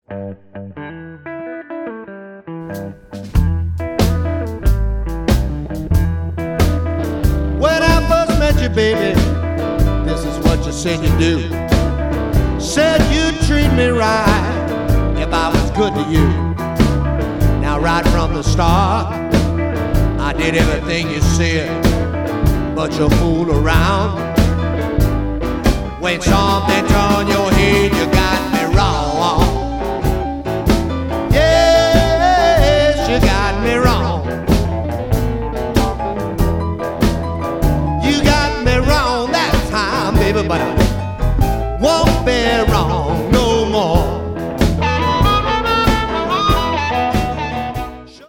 Chicago influenced harp playing